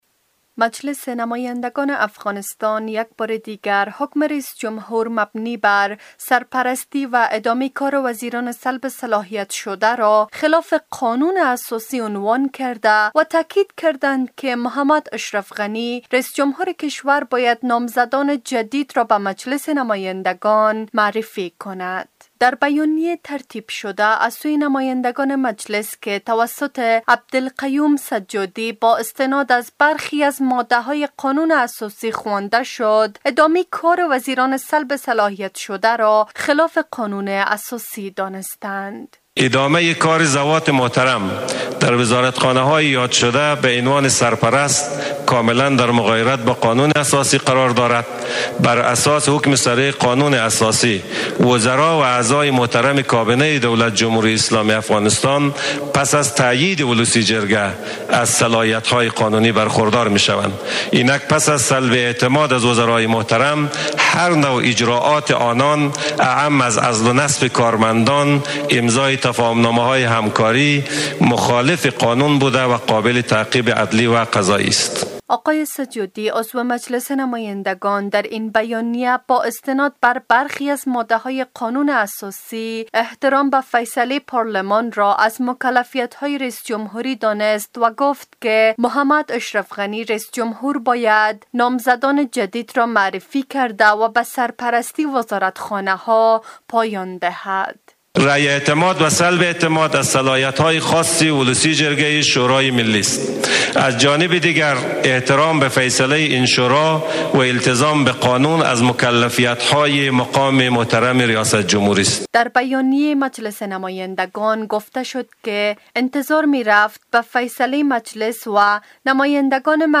جزئیات بیشتر این خبر درگزارش